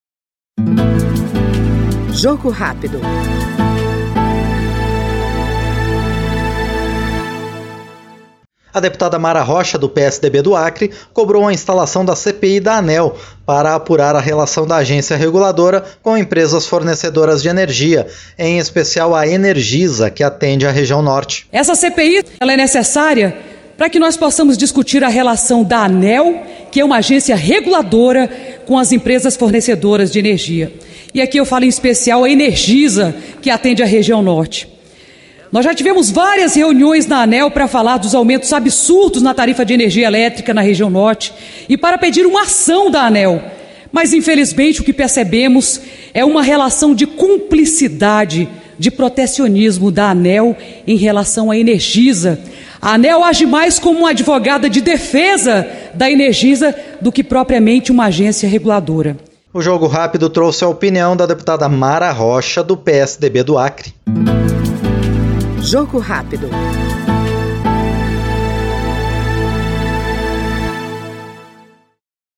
Jogo Rápido é o programa de entrevistas em que o parlamentar expõe seus projetos, sua atuação parlamentar e sua opinião sobre os temas em discussão na Câmara dos Deputados.